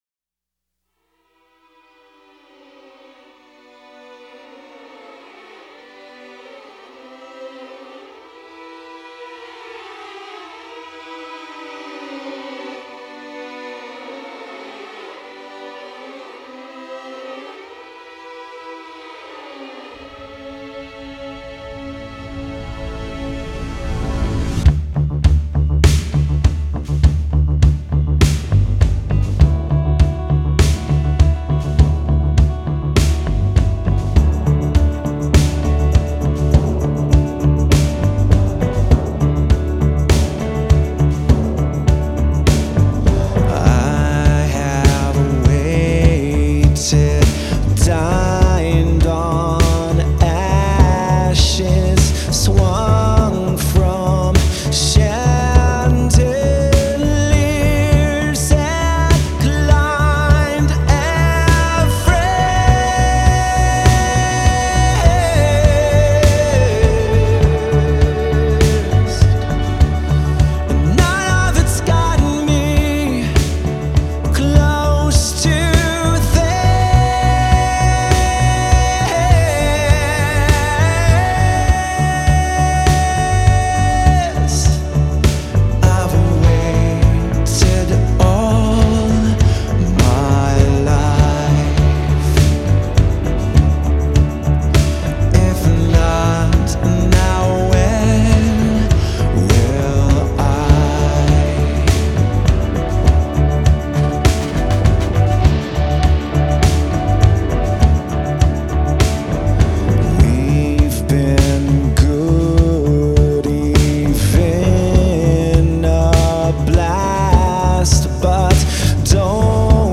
My taste in the emo skews towards alternative rock